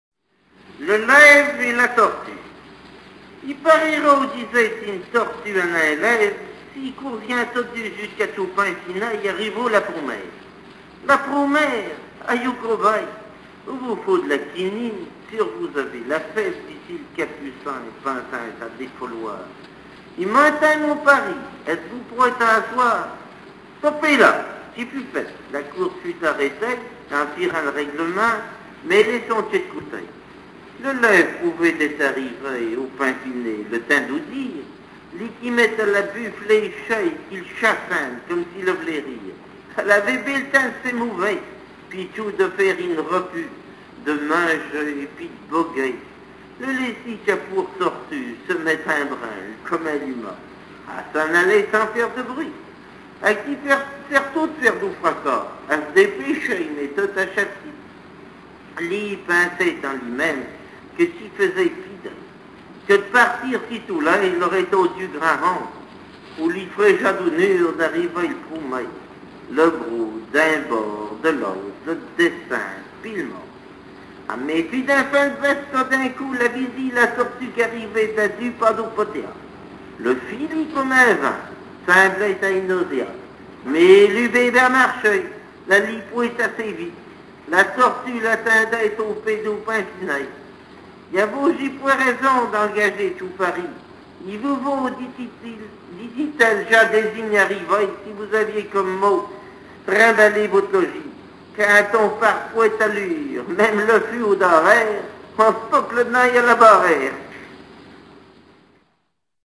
Mémoires et Patrimoines vivants - RaddO est une base de données d'archives iconographiques et sonores.
Langue Maraîchin
Genre fable
Catégorie Récit